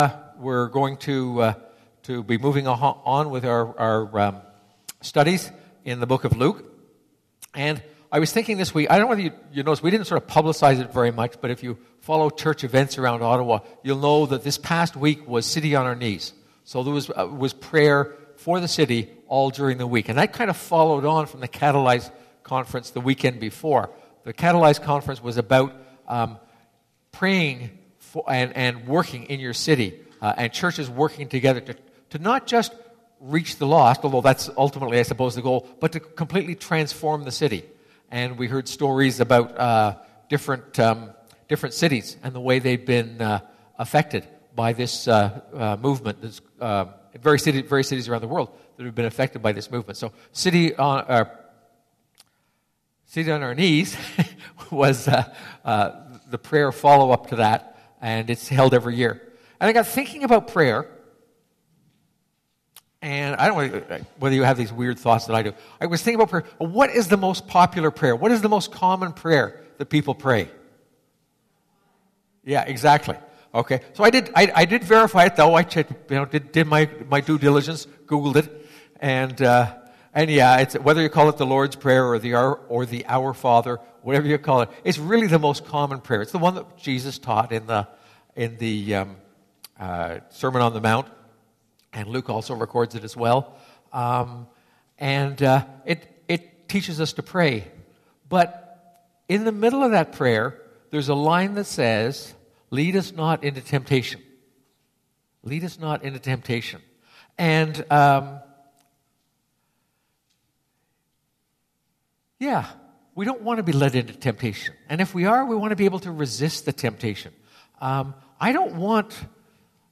This sermon is based on Luke 17:1-6